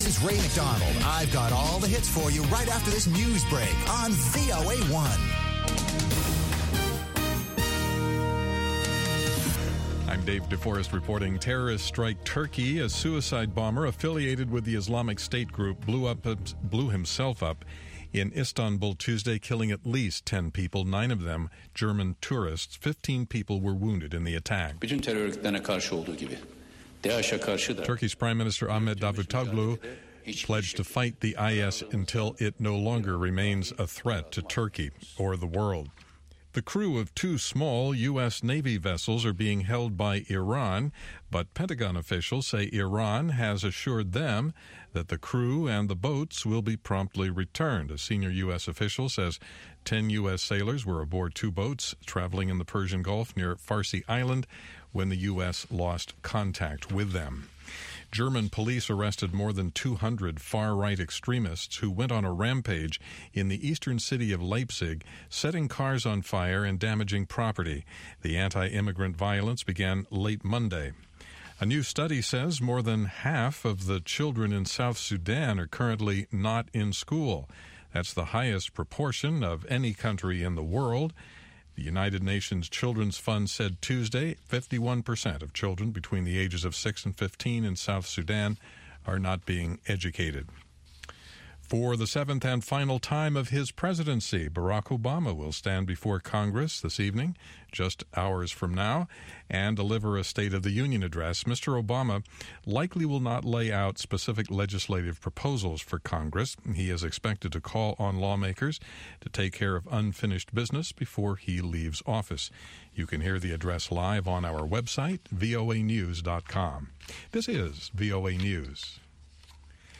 N’dombolo
Benga
African Hip Hop
pan-African music